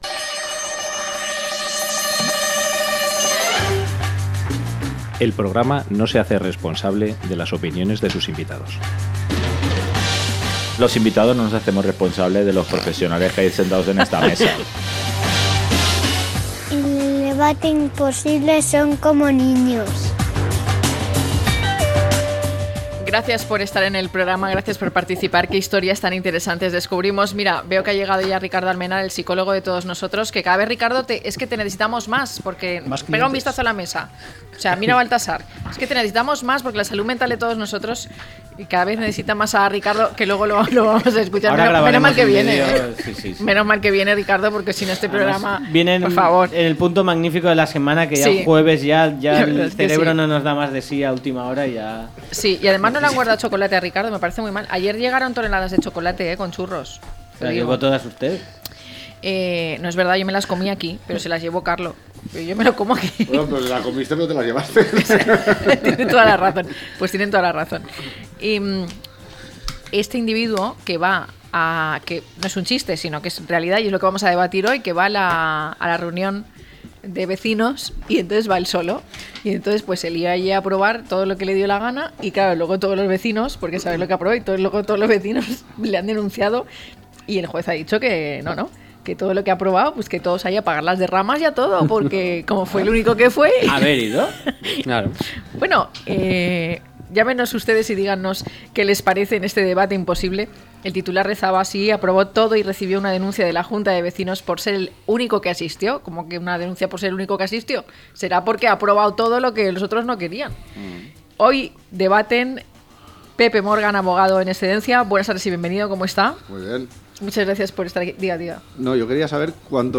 Un solo vecino puede aprobar acuerdos en la junta, aunque reciba una denuncia. Los invitados debaten sobre una reciente sentencia de la Audiencia Provincial de Les Illes Balears, que establece que un único vecino puede aprobar acuerdos en una junta de propietarios si el resto no asiste y la convocatoria fue realizada correctamente.